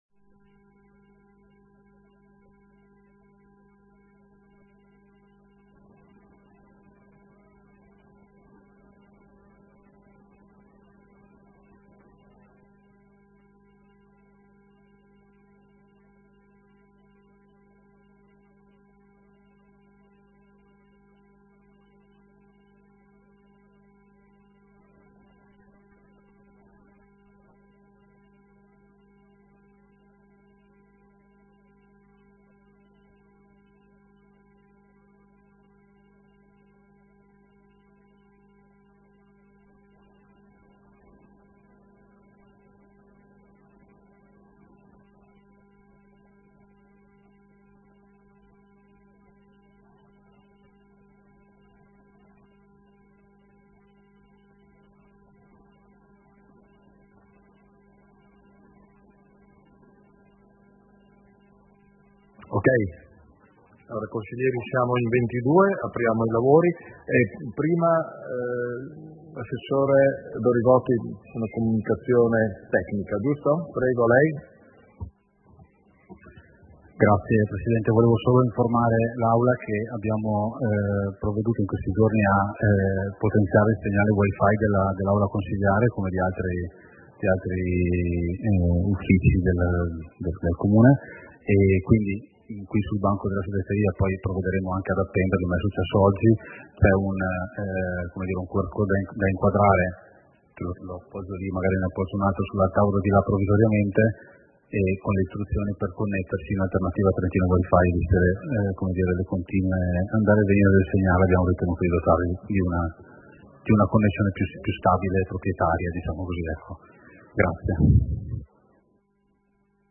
Seduta del consiglio comunale - 09.12.2025